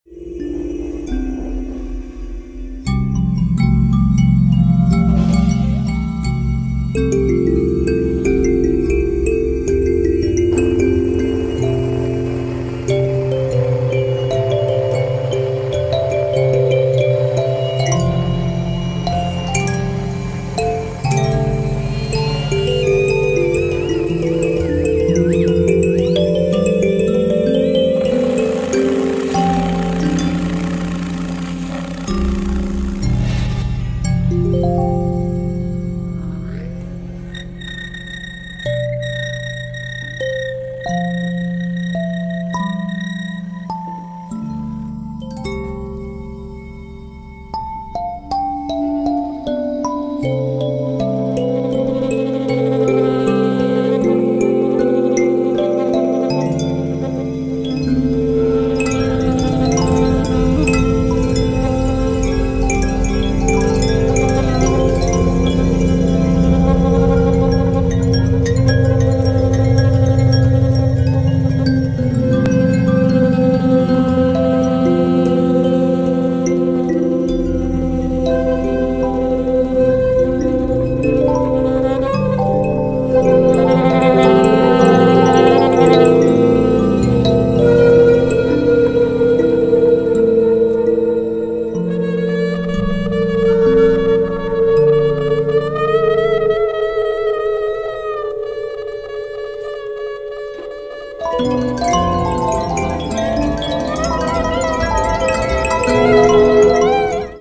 Instant compositions by all musicians,
6 and 14 live at Le Triton